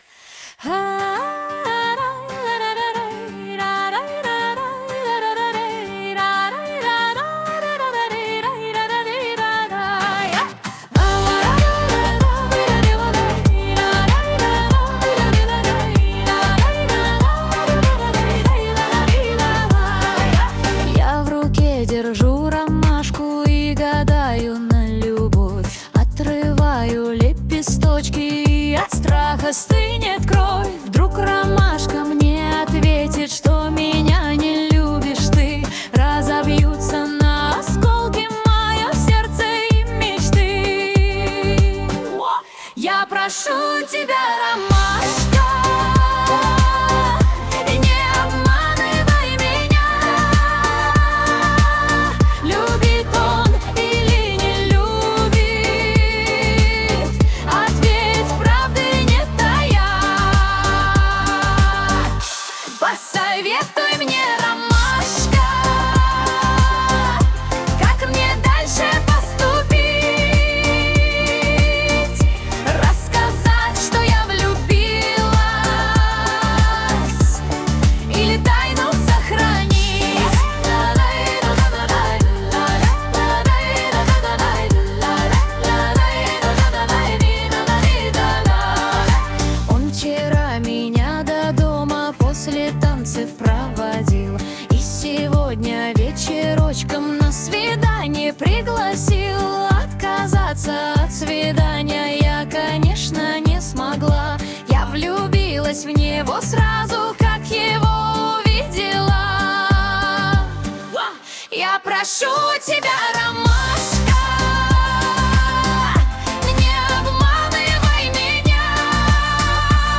Жанр: Фольклор